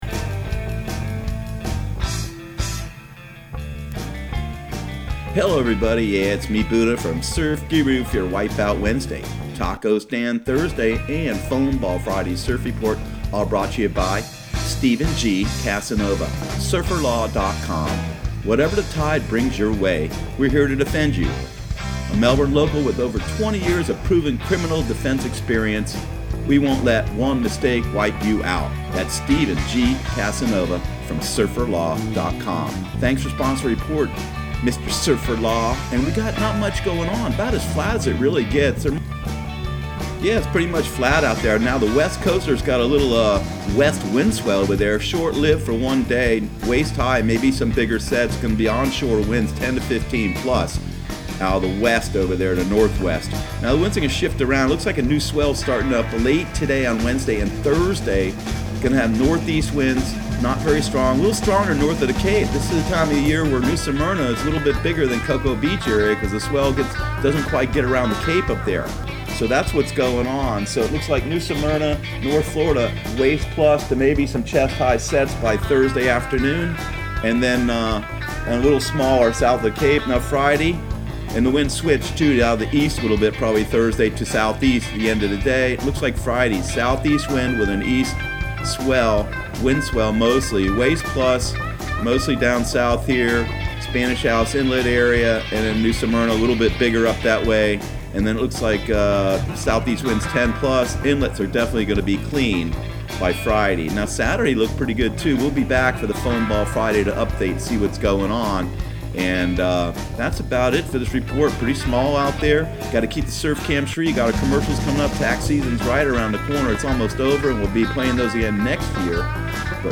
Surf Guru Surf Report and Forecast 04/10/2019 Audio surf report and surf forecast on April 10 for Central Florida and the Southeast.